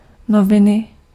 Ääntäminen
IPA : /n(j)uːz/ US : IPA : [njuːz]